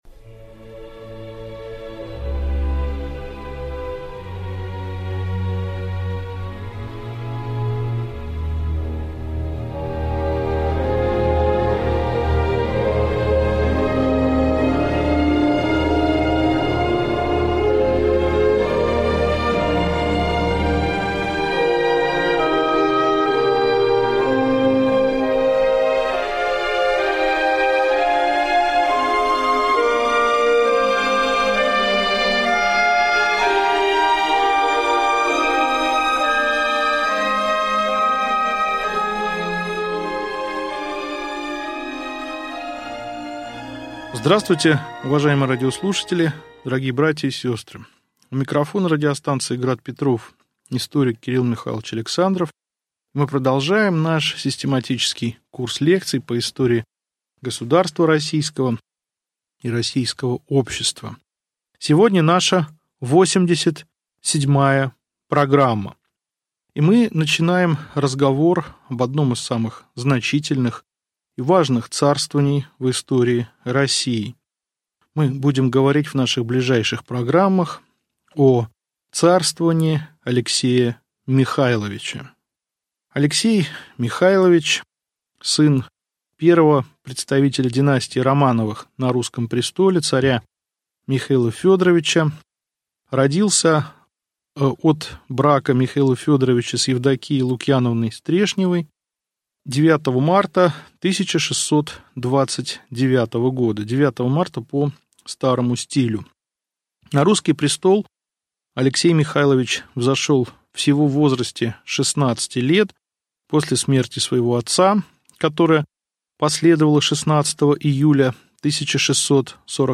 Аудиокнига Лекция 87. Личность царя Алексея Михайловича | Библиотека аудиокниг